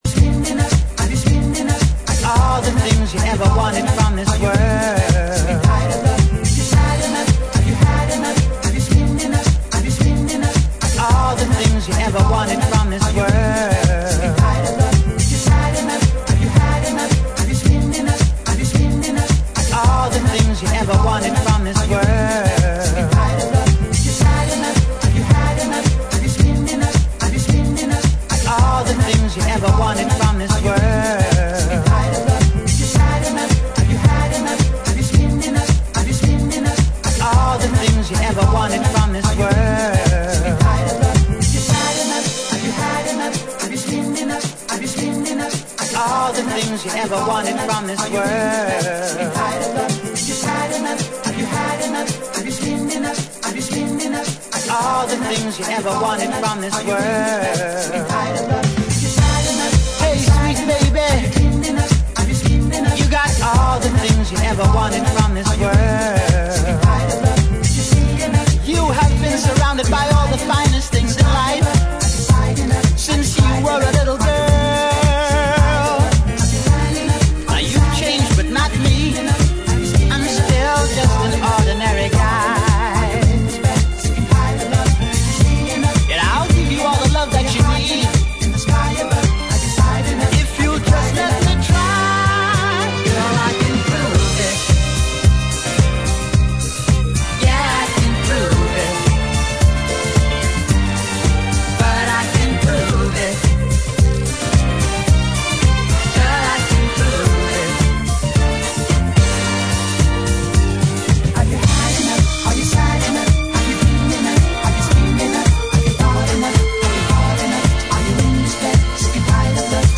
ジャンル(スタイル) CLUB / DANCE / NU DISCO / HOUSE / DISCO